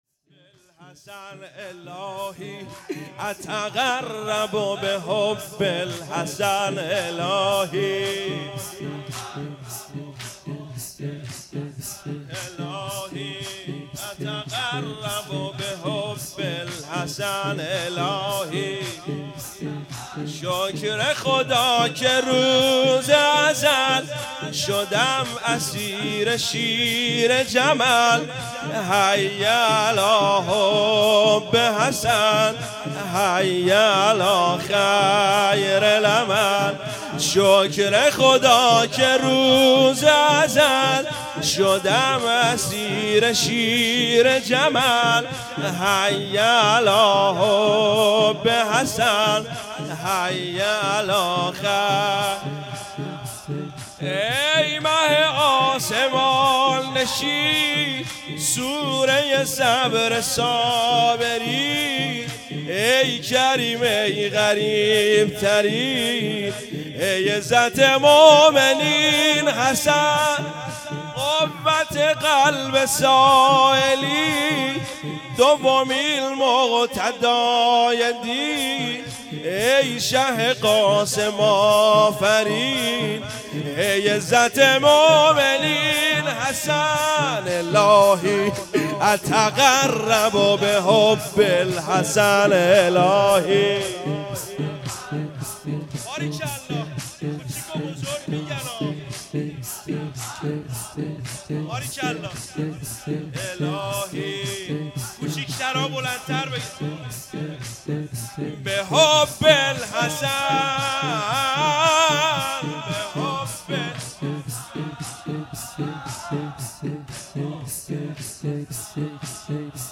مراسم دهه‌ی آخر صفر۹۸-شب دوم ۵ آبان